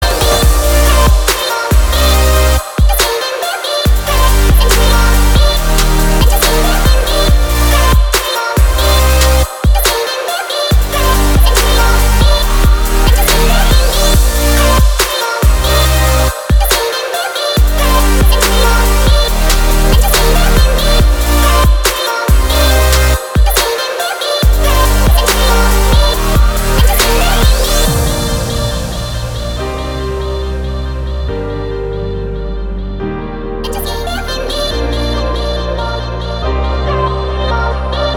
• Качество: 320, Stereo
Electronic
забавный голос
future bass
Chill
Интересный и ритмичный